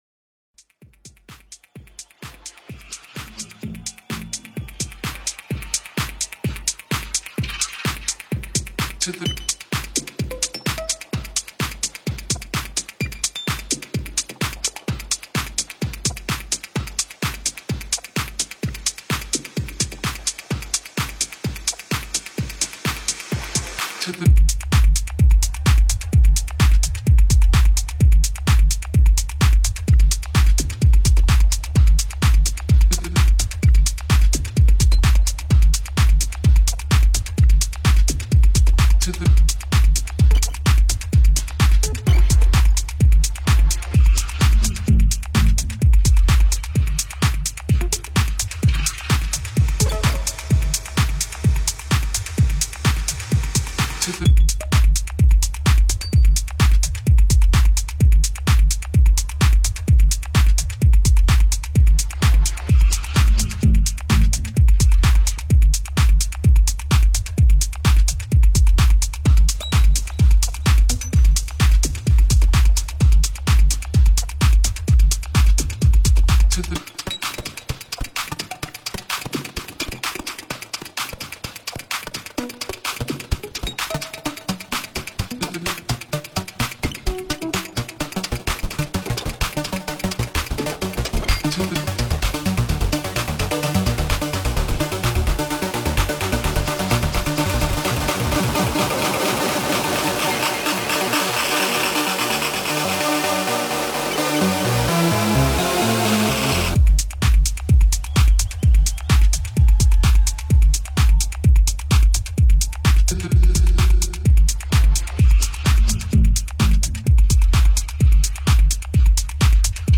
Also find other EDM